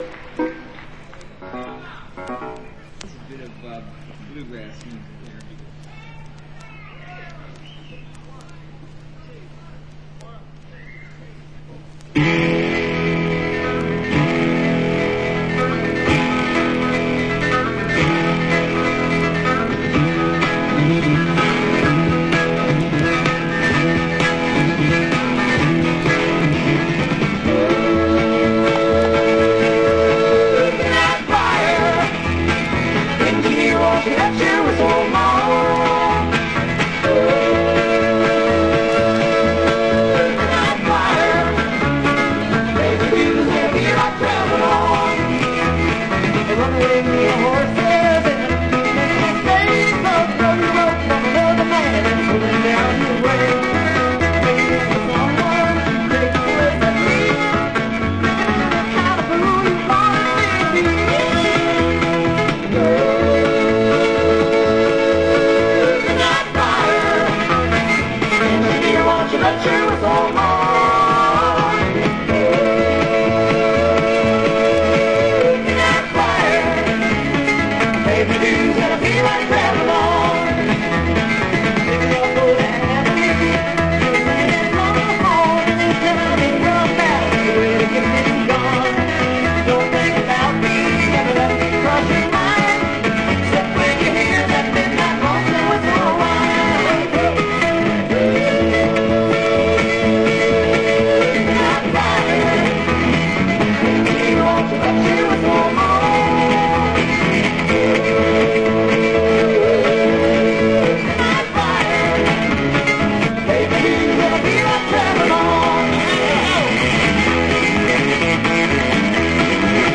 Hordern Pavilion, Sydney, Australia
slide guitar